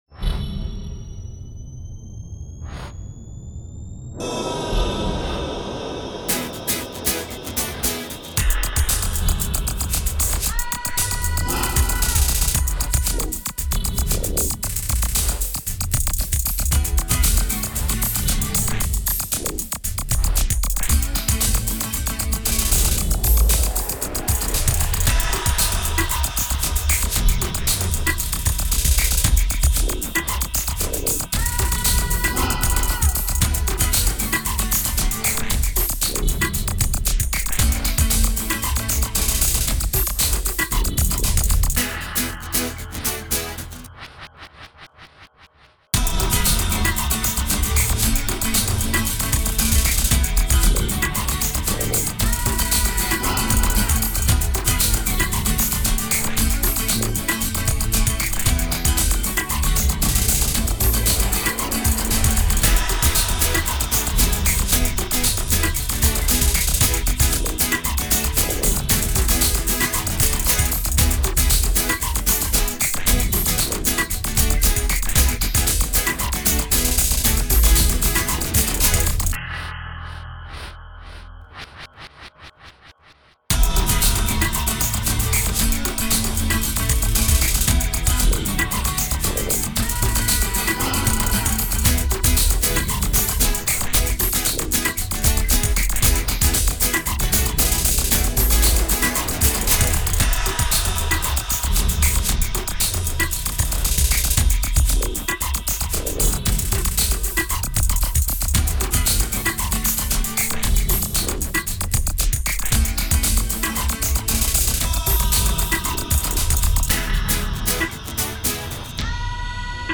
Коротенькая зарисовка! Сэмплы в большинстве чужие, и со стилем вообще непонятно!